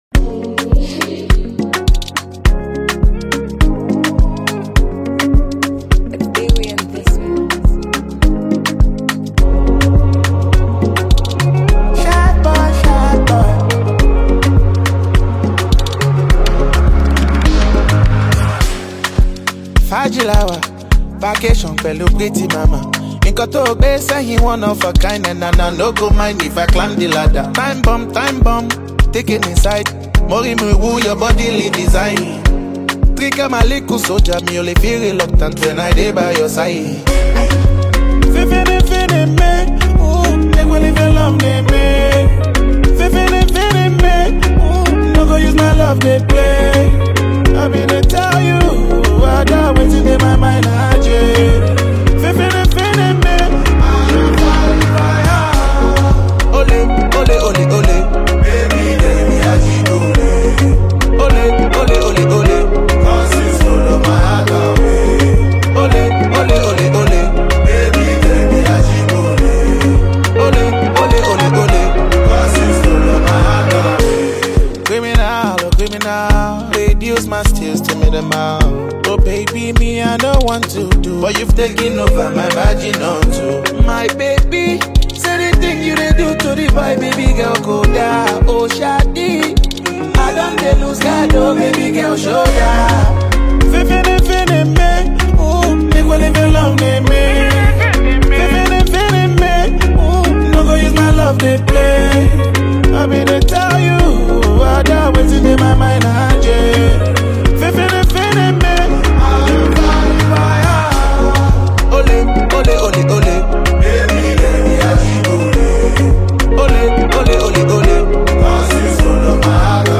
Built on a hard-hitting beat and laced with street slang